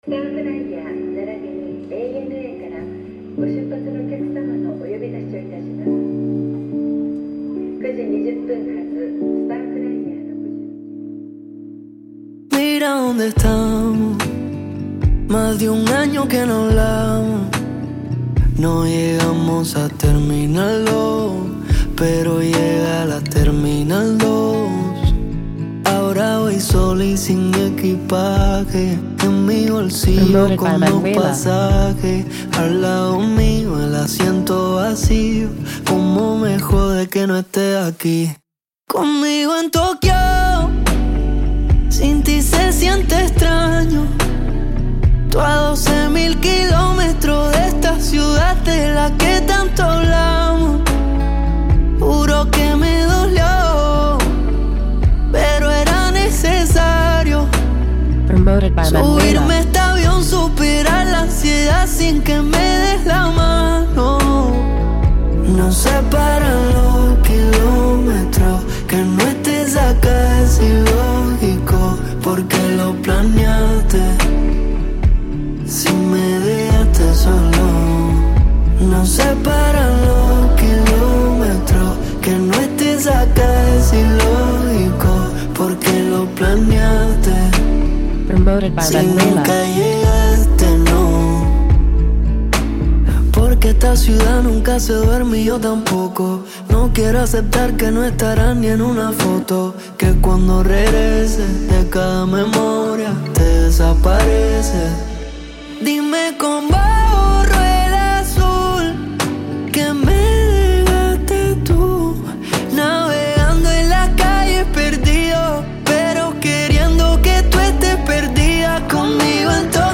Cette chanson poignante
Avec des sonorités pop et des paroles introspectives, "12,0
Radio Edit